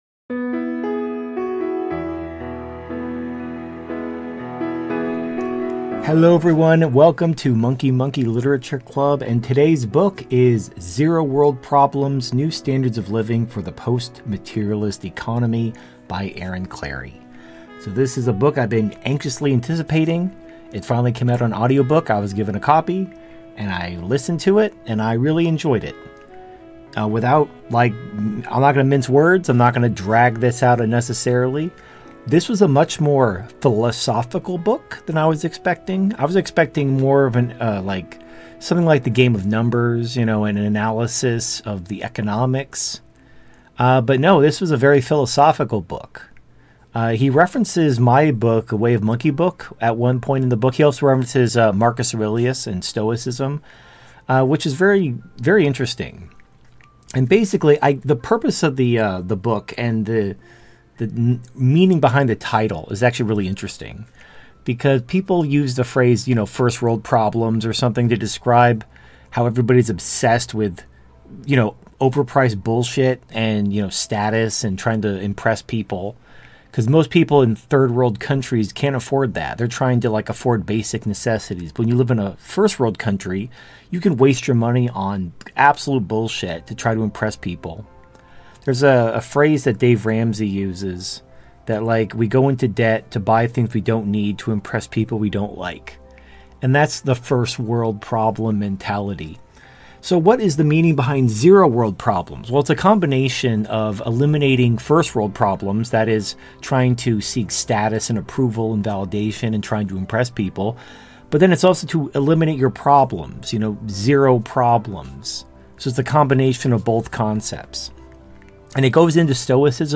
Book Review: Zero World Problems by Aaron Clarey